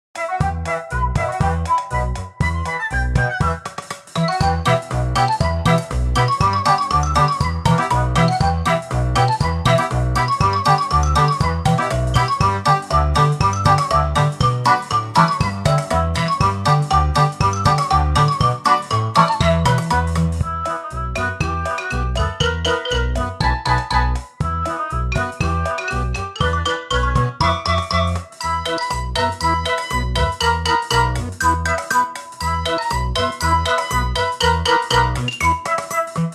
• Качество: 320, Stereo
громкие
забавные
веселые
без слов
из игр